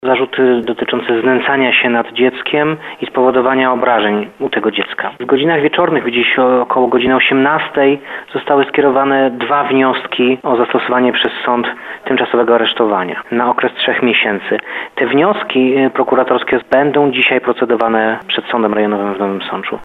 Jak informuje Bartosz Gorzula, Prokurator Rejonowy w Nowym Sączu, po wtorkowych (13.01) przesłuchaniach zarzuty w tej sprawie usłyszał także partner matki dziecka.